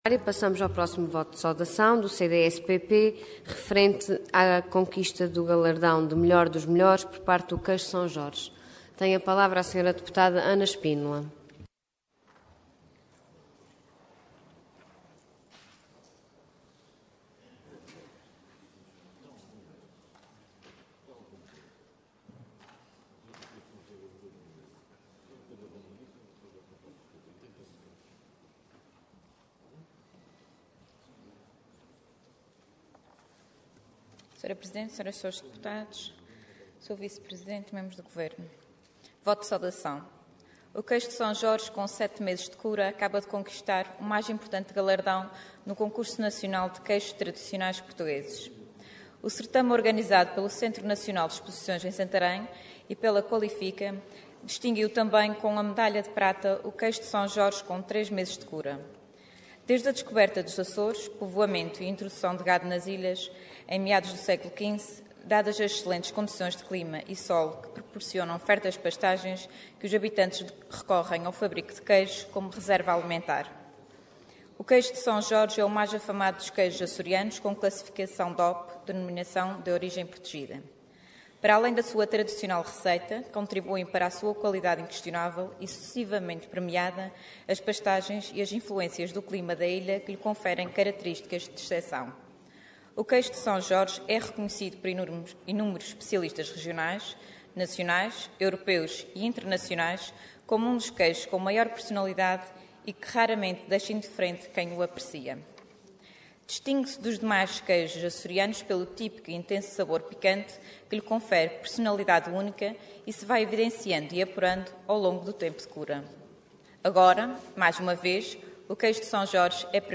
Detalhe de vídeo 10 de abril de 2014 Download áudio Download vídeo Processo X Legislatura Queijo de São Jorge é o "Melhor dos Melhores" Intervenção Voto de Saudação Orador Ana Espínola Cargo Deputada Entidade CDS-PP